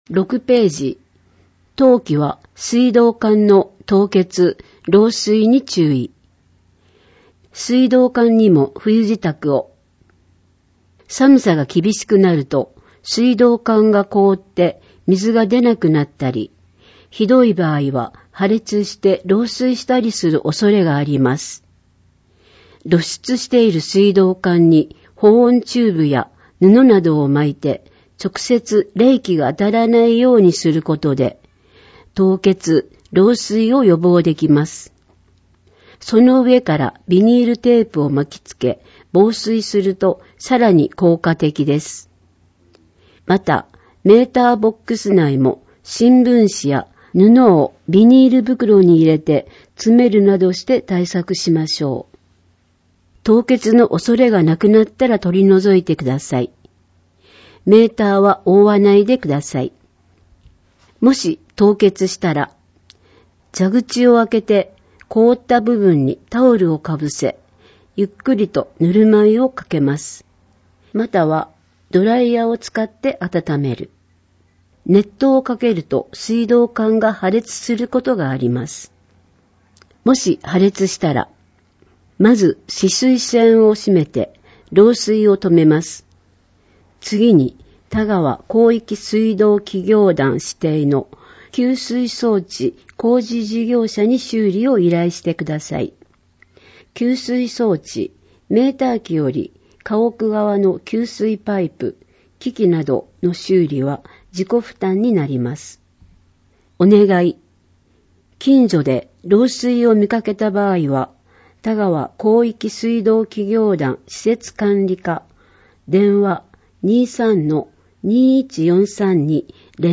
『広報かわさき』を音訳している川崎町朗読ボランティア「ひまわり」の作成した音声データを掲載しています。